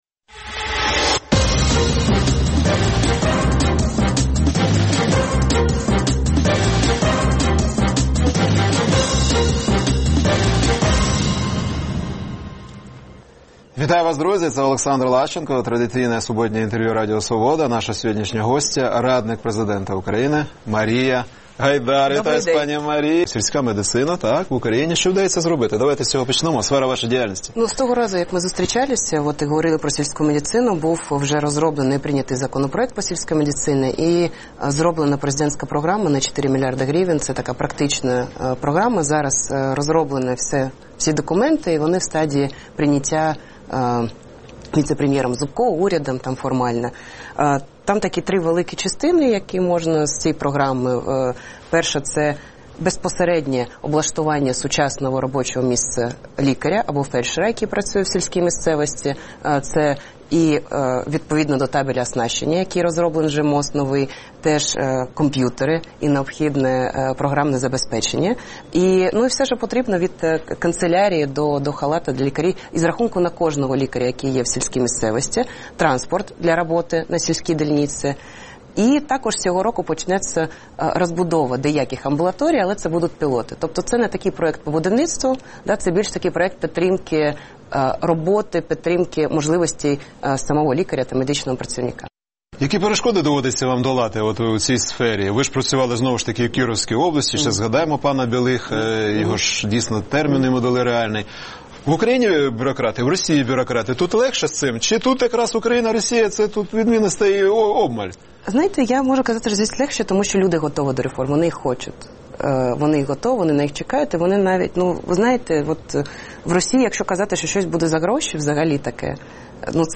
Суботнє інтерв’ю | Марія Гайдар, радник президента України, депутат Одеської облради